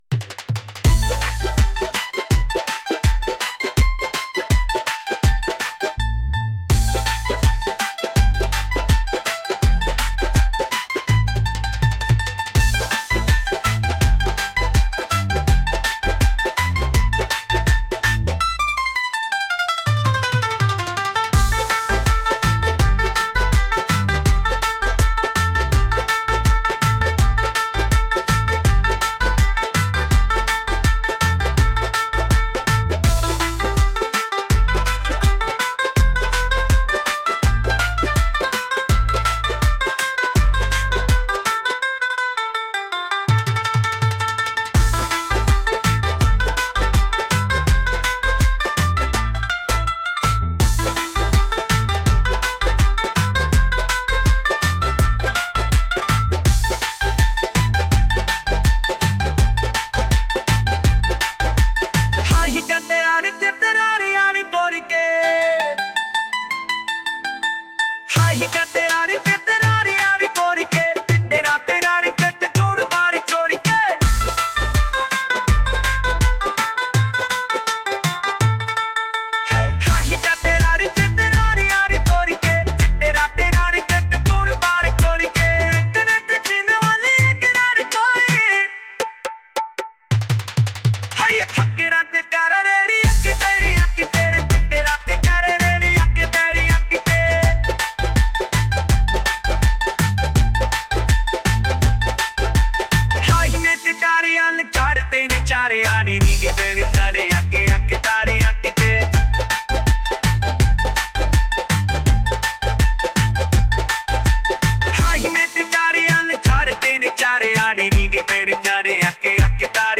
energetic | upbeat